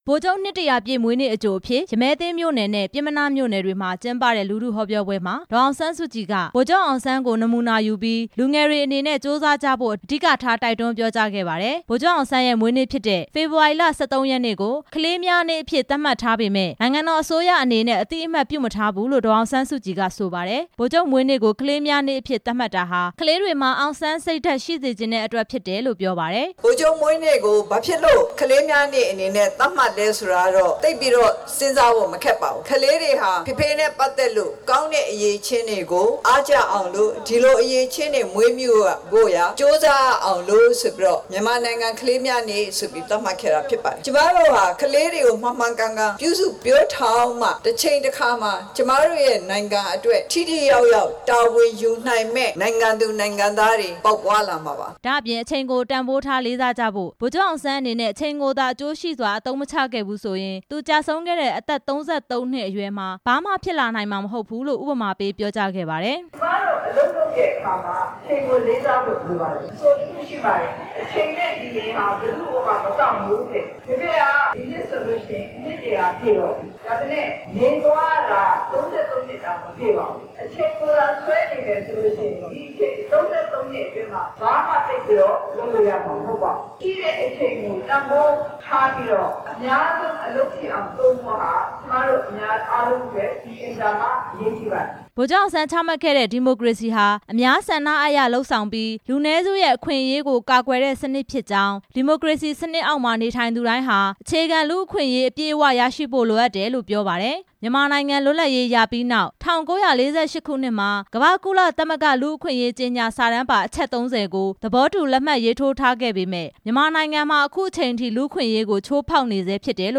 မန္တလေးနဲ့ နေပြည်တော်က ဗိုလ်ချုပ်နှစ်တစ်ရာပြည့် လူထုဟောပြောပွဲ
ဗိုလ်ချုပ်နှစ်တစ်ရာပြည့်မွေးနေ့အကြို လူထုဟောပြောပွဲကို မန္တလေးတိုင်းဒေသကြီး ရမည်းသင်း မြို့နယ်က ရွှေစည်းခုံ ဘုရားဝင်းနဲ့ နေပြည်တော် ပျဉ်းမနားမြို့နယ်က ကင်ပွန်းတန်းကွင်းမှာ ဒီနေ့ ကျင်းပခဲ့ရာ အမျိုးသားဒီမိုကရေစီအဖွဲ့ချုပ် ဥက္ကဌ ဒေါ်အောင်ဆန်းစုကြည် တက်ရောက်မိန့်ခွန်း ပြောခဲ့ပါတယ်။